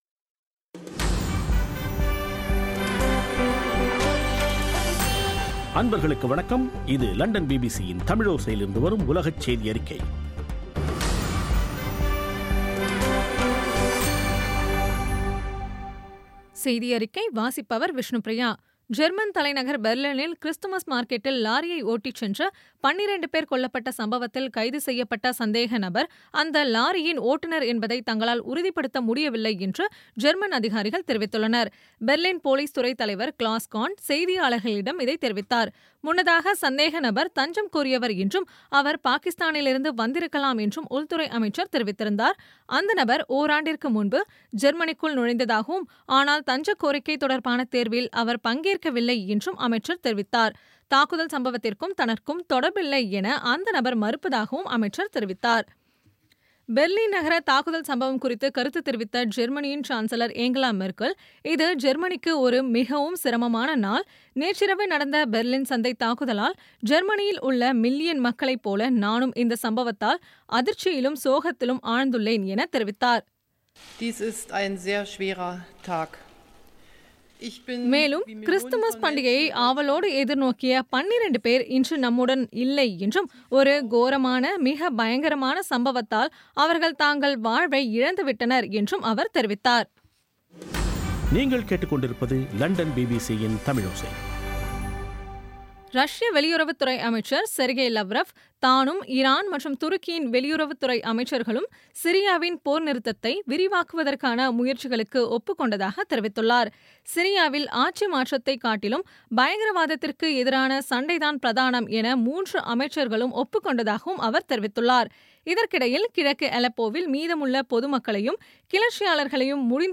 பிபிசி தமிழோசை செய்தியறிக்கை (20/12/2016)